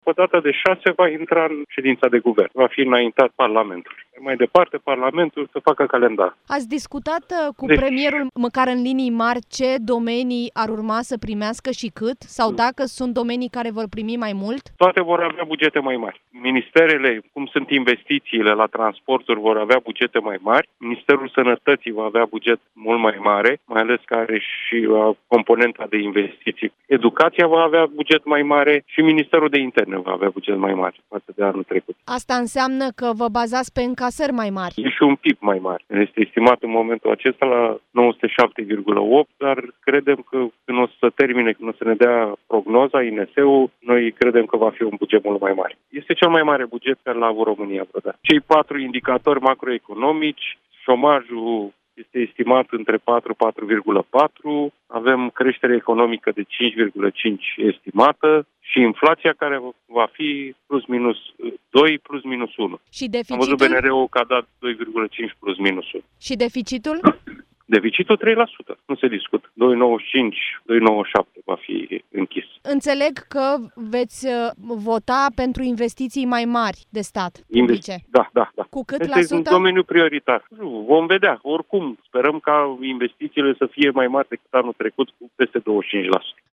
Într-un interviu acordat Europa FM, vicepremierul Marcel Ciolacu precizează că, anul viitor, România va avea un produs intern brut record, de peste 907 milioane de lei.
04-dec-07-interviuri-Marcel-Ciolacu-astazi-fixam-bugetul-.mp3